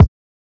Kick (Impossible).wav